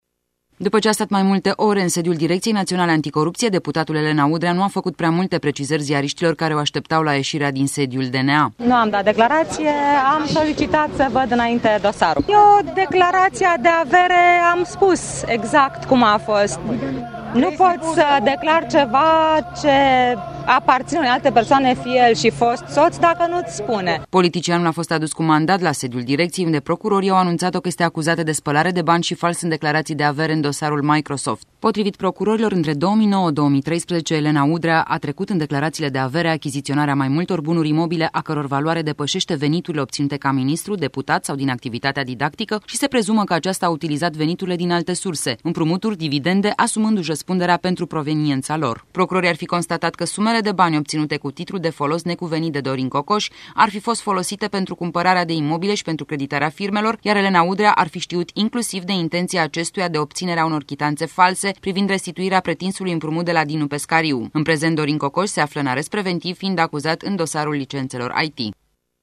Legat de acuzaţia de fals în declaraţia de avere Elena Udrea a subliniat: